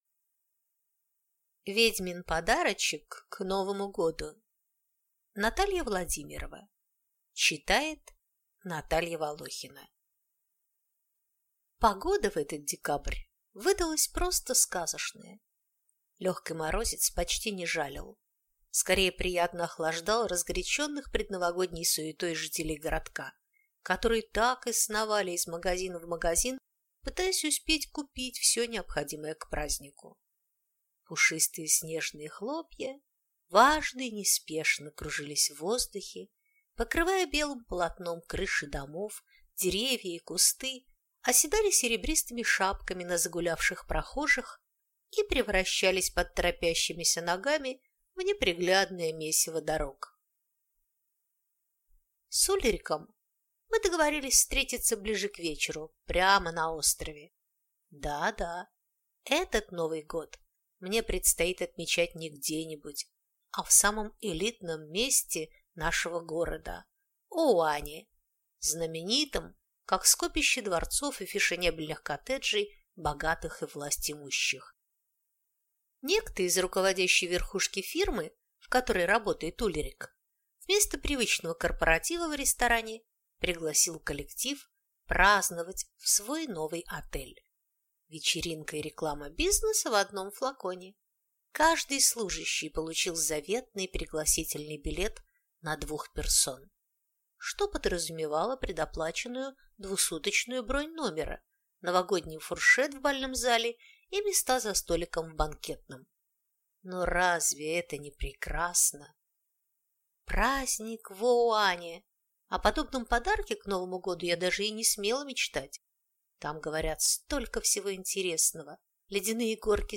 Аудиокнига Ведьмин подарочек к Новому году | Библиотека аудиокниг
Прослушать и бесплатно скачать фрагмент аудиокниги